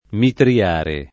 mitrare v.; mitro [m&tro] — anche mitriare [